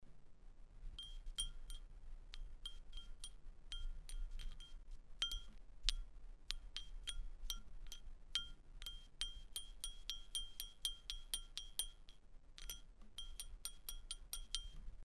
Sound recordings of original bells from Ovila, Roman Wels (AT
sound of original bell 309 0.23 MB
bell_309_Ovilava.mp3